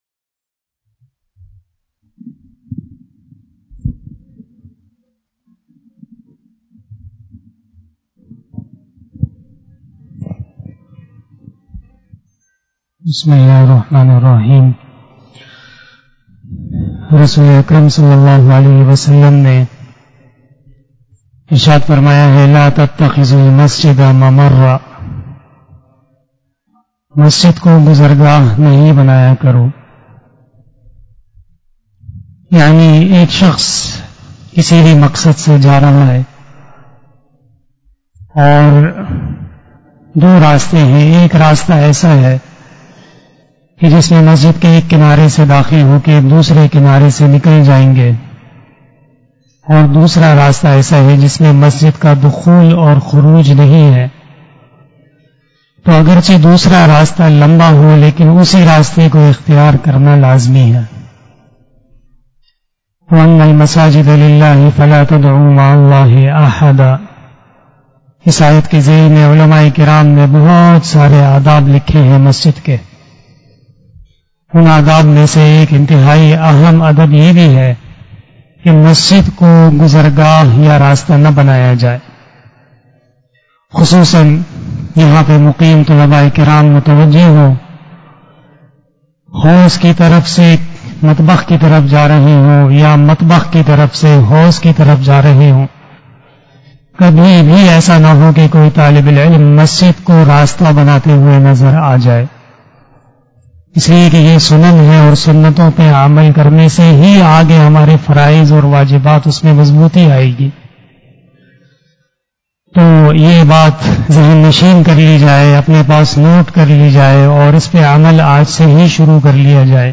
028 After Asar Namaz Bayan 26 June 2021 ( 15 Zulqadah 1442HJ) Saturday